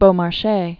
(bō-mär-shā), Pierre Augustin Caron de 1732-1799.